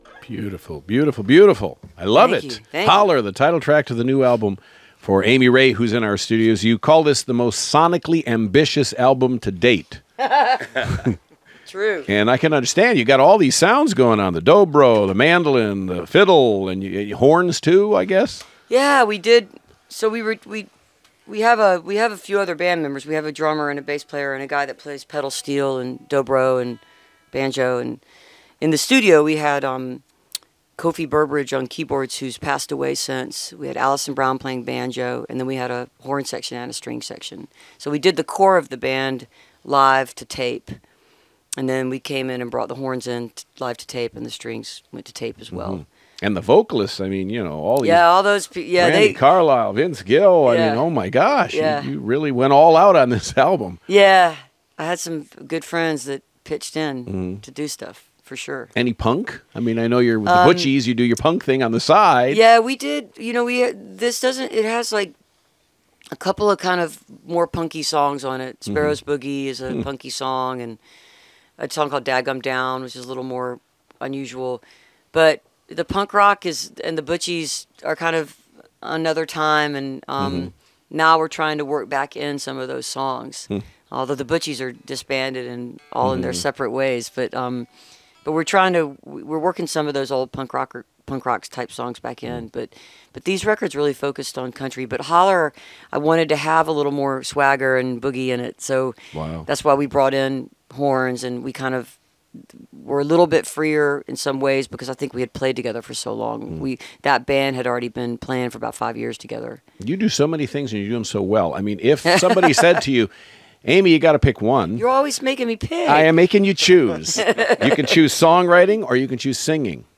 (recorded from a webcast)
06. interview (3:53)